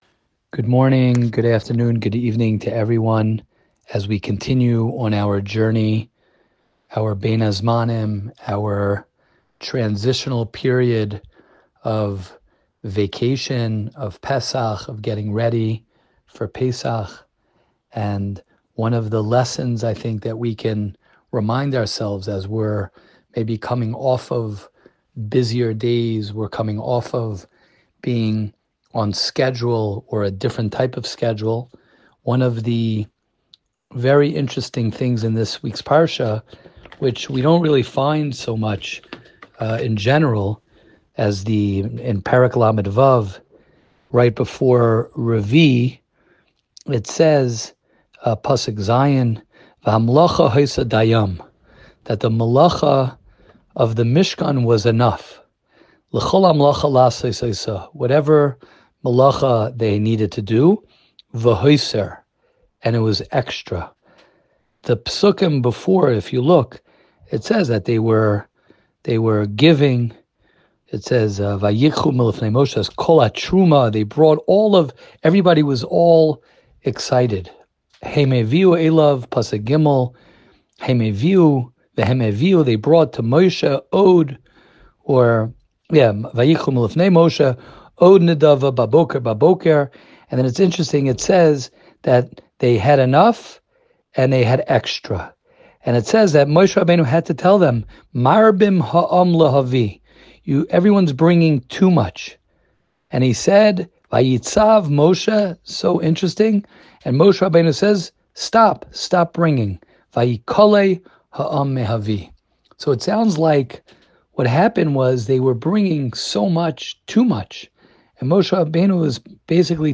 Bein Hazmanim Shiur